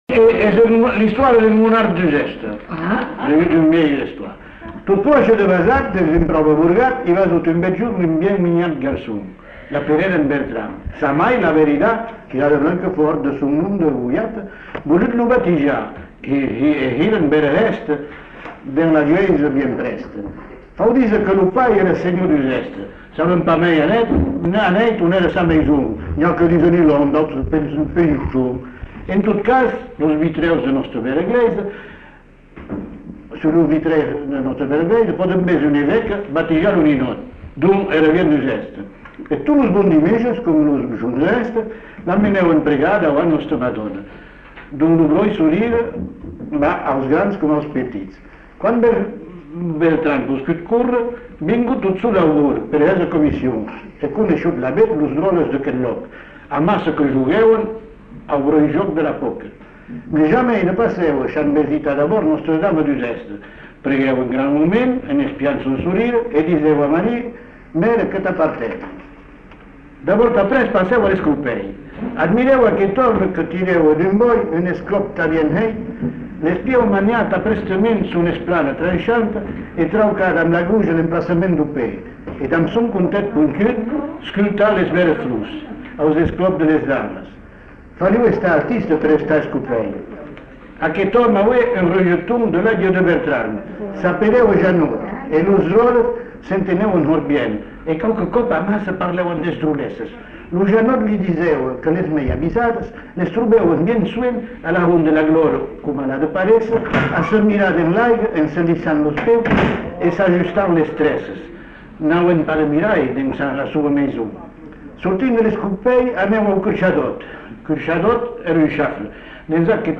Lieu : Uzeste
Genre : conte-légende-récit
Effectif : 1
Type de voix : voix d'homme
Production du son : lu
Classification : monologue